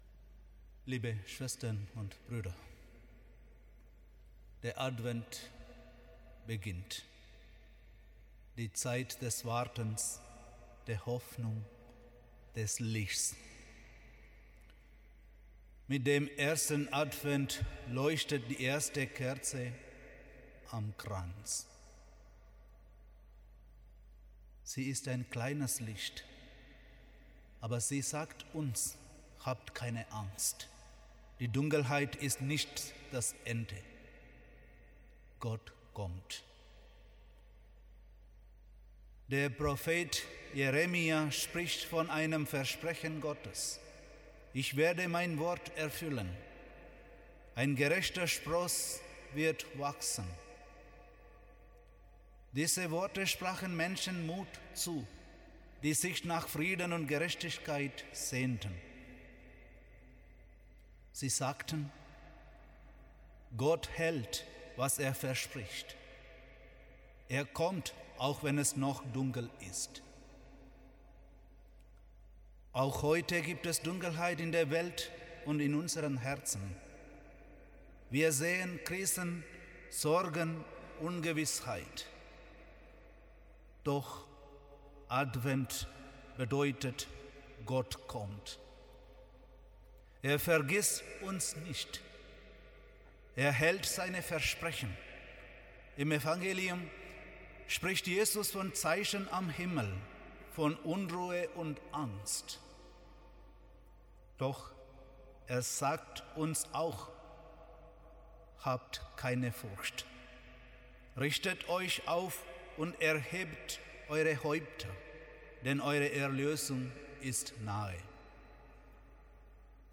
Hier finden Sie die Predigten unserer Seelsorger als Audiodateien zum Nachhören.